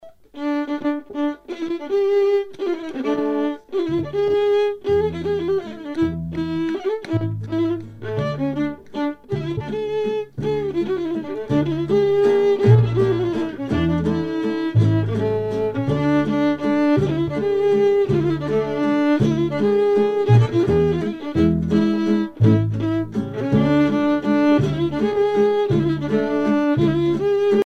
danse : kolo (Serbie)
Pièce musicale éditée